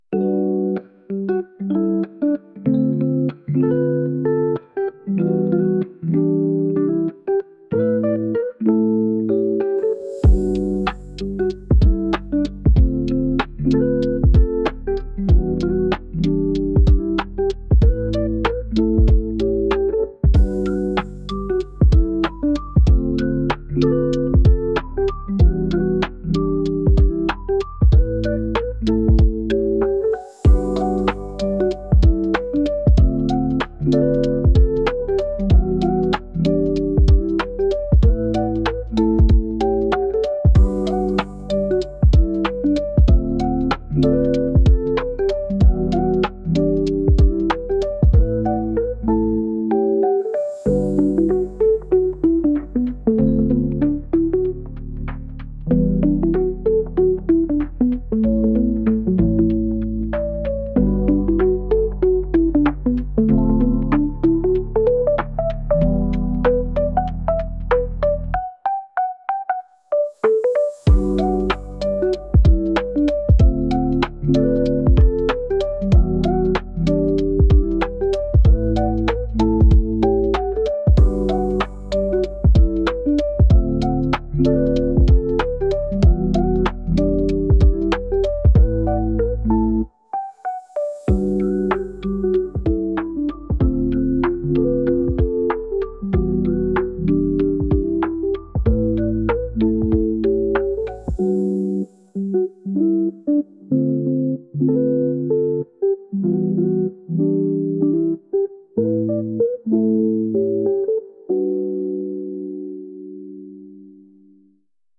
雨がやまなくてどうにもならないまったりした曲です。